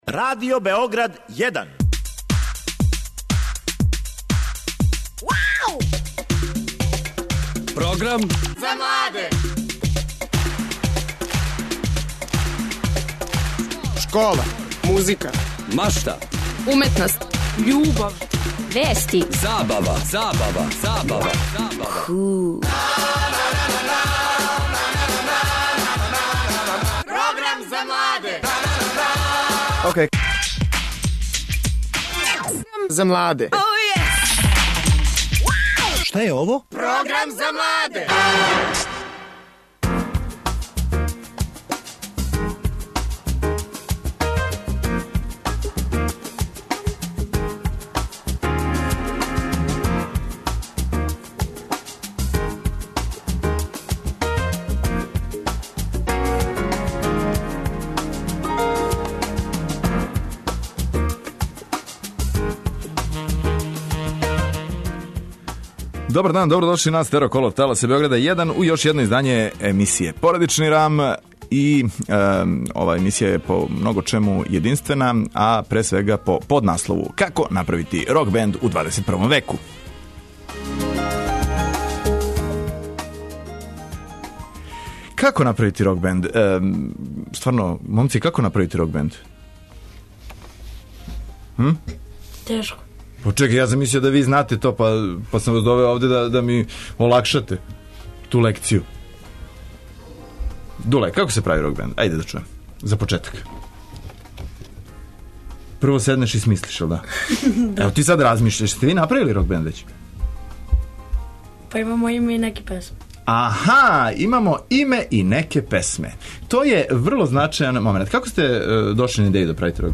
Иако још немају инструменте, о томе говоре гости емисије, десетогодишњаци, као и и њихови родитељи.